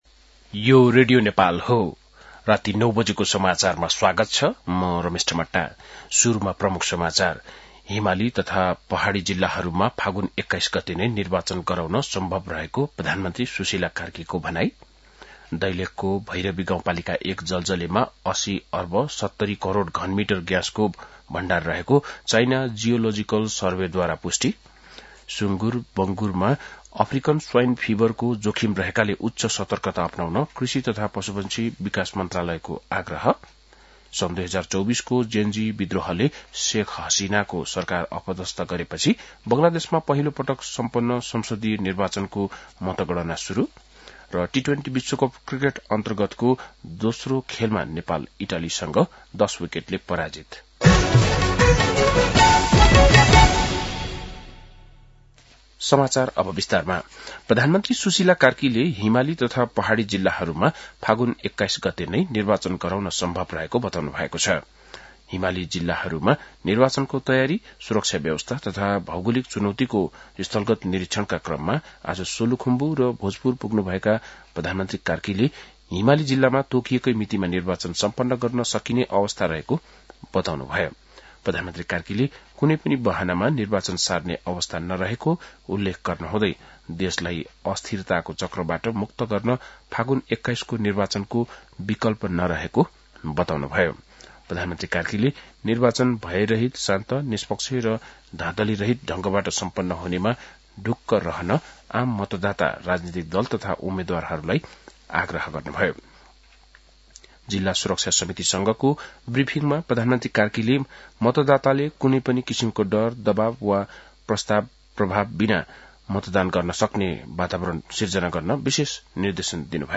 बेलुकी ९ बजेको नेपाली समाचार : २९ माघ , २०८२
9-pm-nepali-news-1-4.mp3